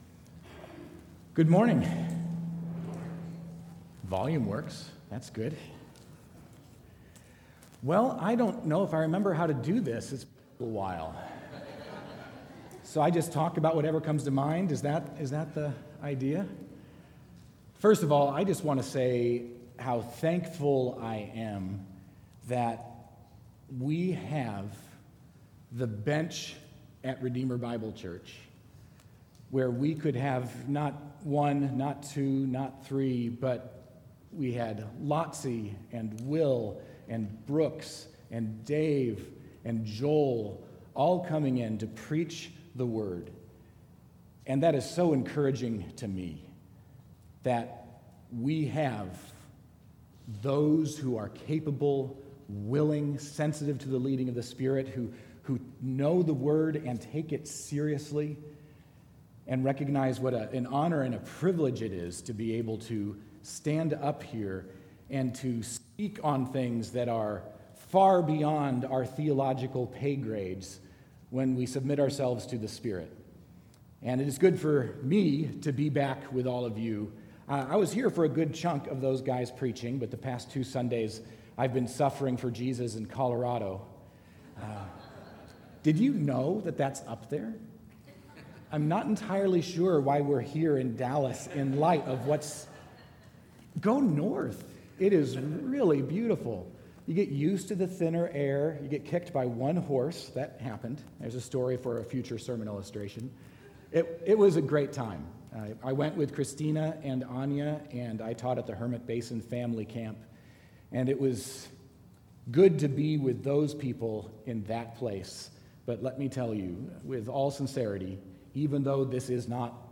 Sermon8.11.19.mp3